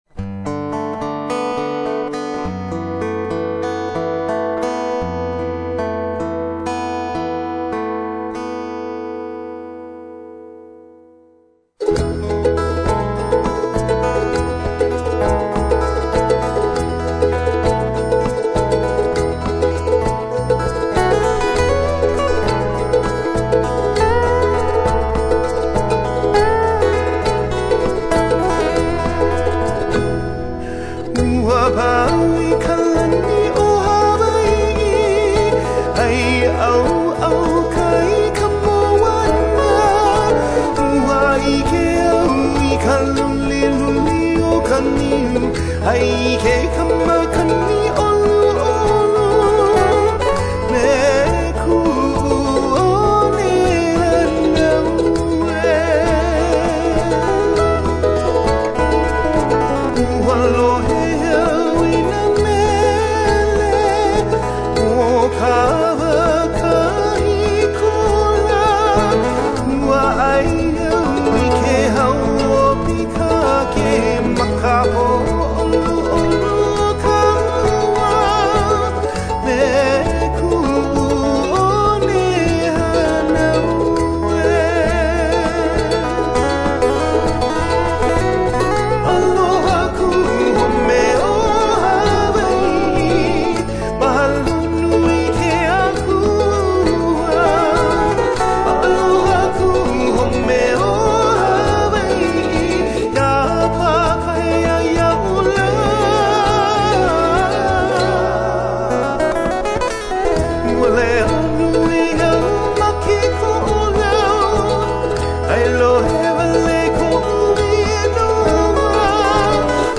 Hawaiian-Singer-1-Me-Kuu-One-Hanau-E.mp3